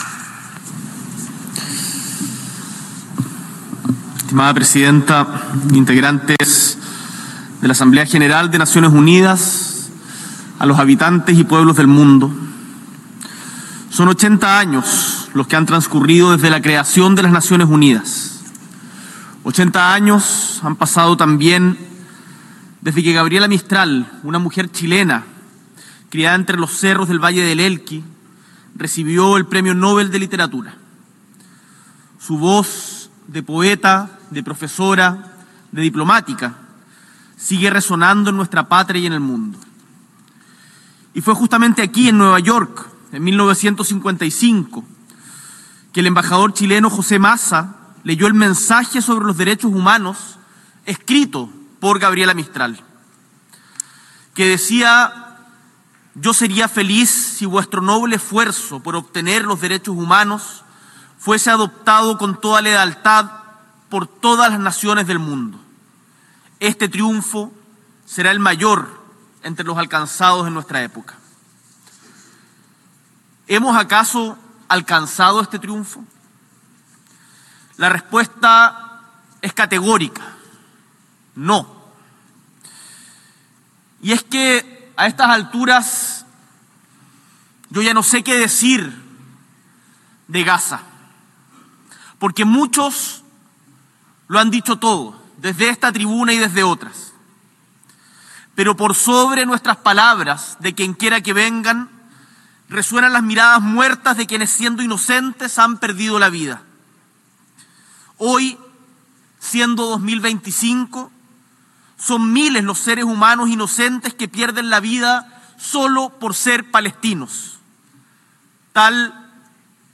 S.E. El Presidente de la República, Gabriel Boric Font, realiza intervención en el Debate General de la 80° sesión de la Asamblea General de Naciones Unidas
Discurso